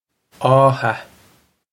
Átha aw-ha
Pronunciation for how to say
This is an approximate phonetic pronunciation of the phrase.